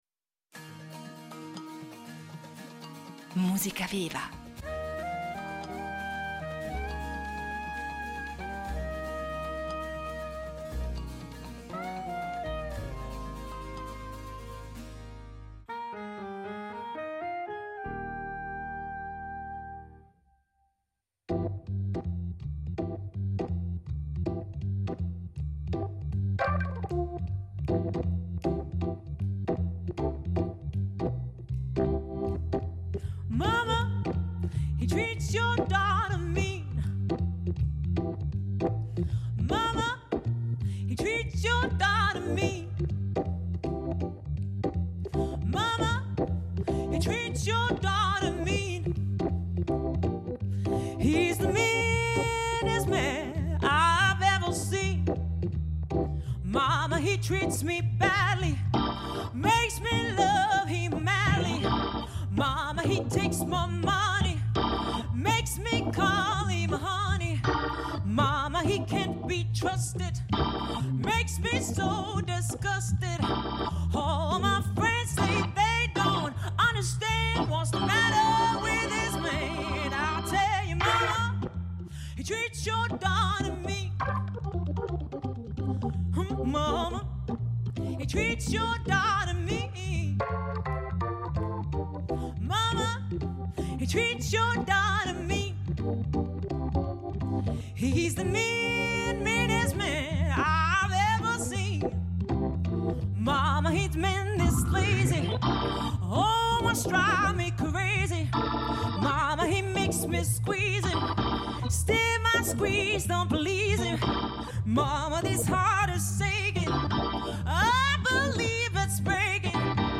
Tra soul, Blues e Gospel.
voce
hammond
in diretta su Rete Due
Soul, Blues e Gospel